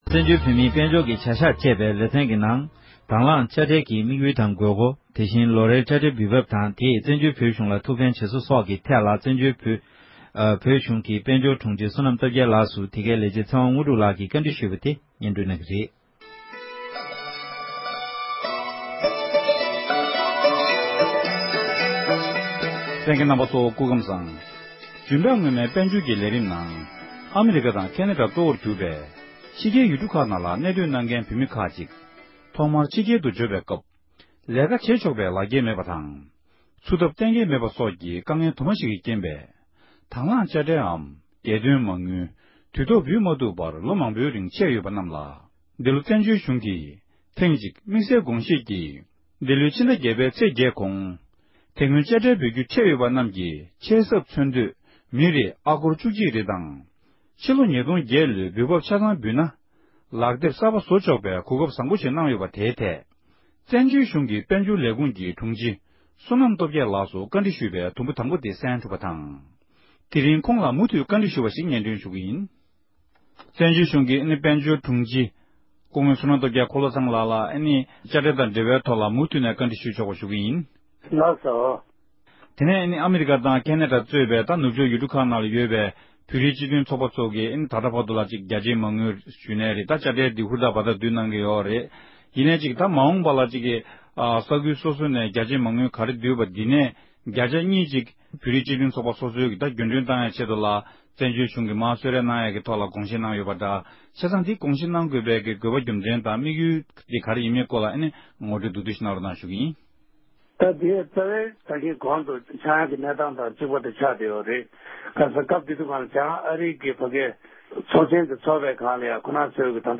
གནས་འདྲི་ཞུས་ཏེ་ཕྱོགས་སྒྲིག་ཞུས་པར་གསན་རོགས་གནང༌༎